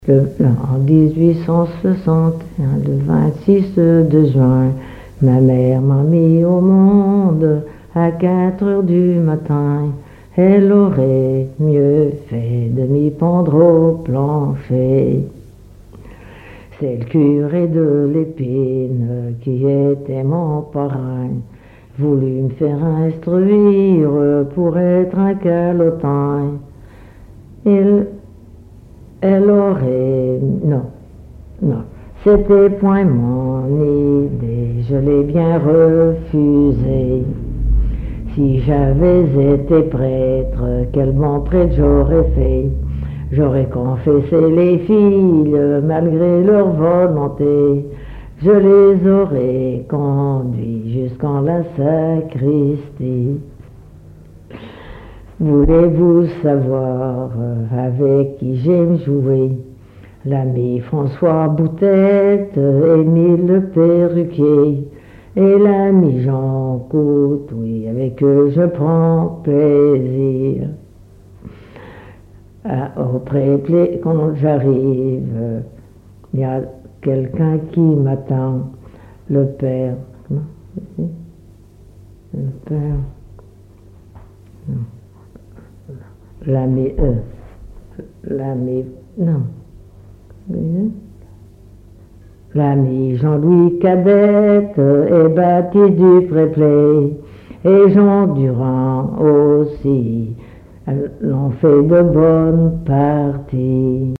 collecte en Vendée
Témoignages et chansons traditionnelles
Pièce musicale inédite